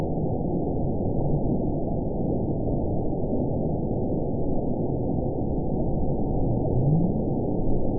event 917796 date 04/16/23 time 23:21:24 GMT (2 years ago) score 9.30 location TSS-AB03 detected by nrw target species NRW annotations +NRW Spectrogram: Frequency (kHz) vs. Time (s) audio not available .wav